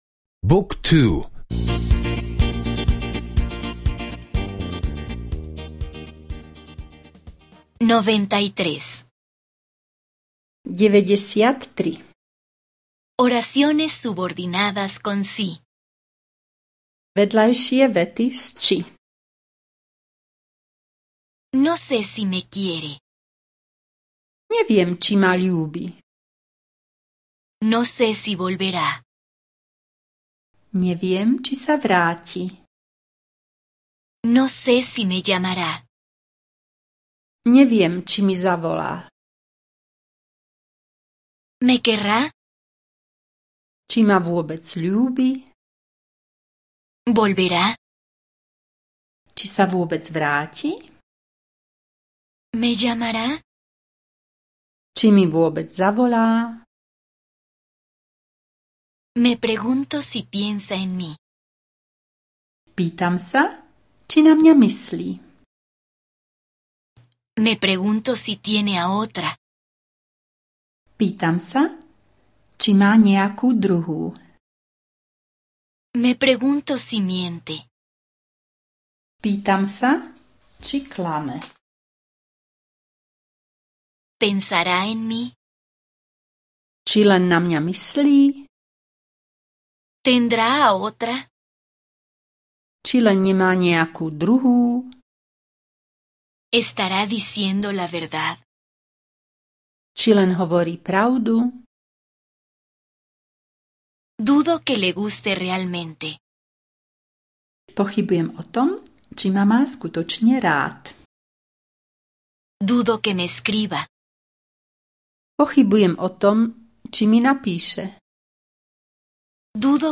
Curso de audio de eslovaco (escuchar en línea)